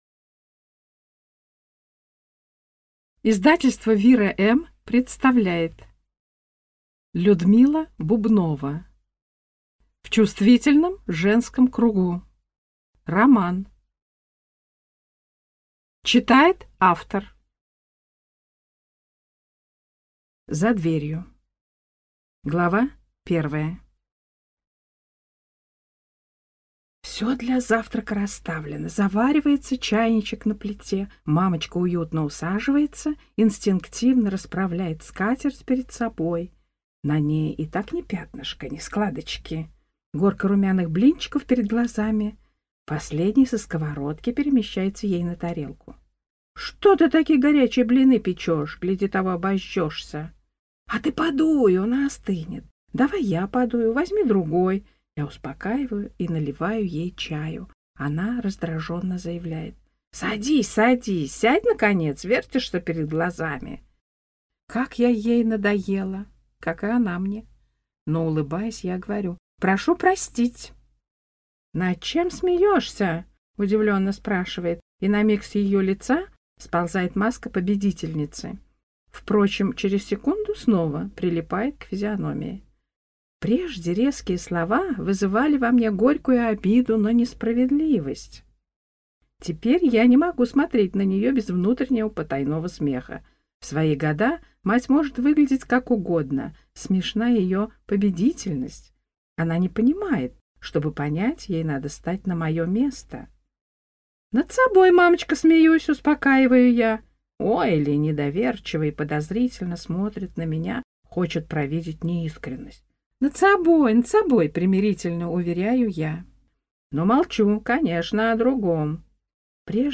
Aудиокнига В чувствительном женском кругу